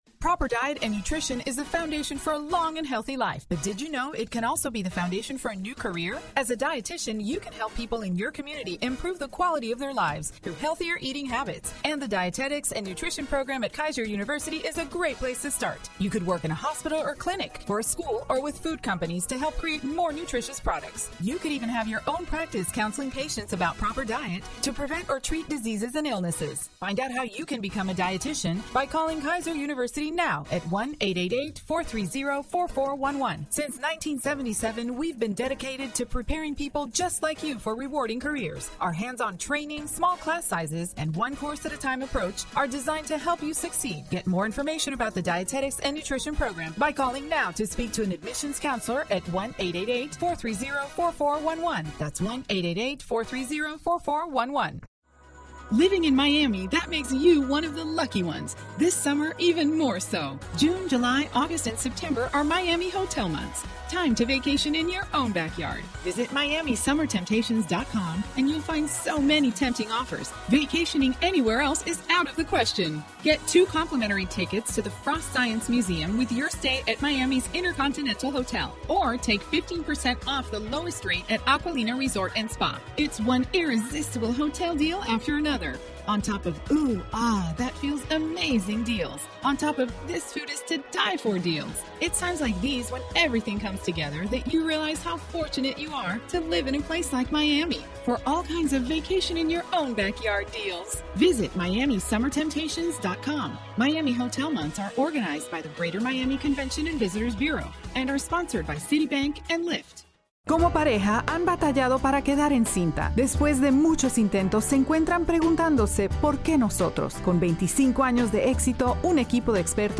Some samples of radio VO spots